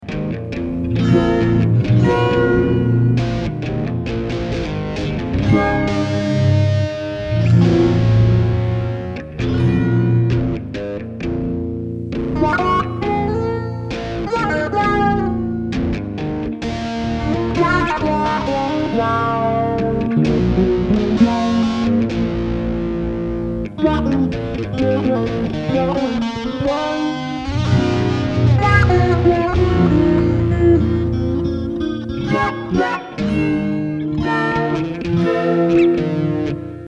Indeed in the EH Tube Zipper, here is another unique sounding pedal which I do not find duplicated in the DSP realm or from other manufacturers. The Zipper design is basically 4 tube stages being very high gained and over driven into a fuzz tone effect but with an auto wah filter circuit in the middle (like a Dunlop wah wah, etc. but no foot pedal to manually vary the wah).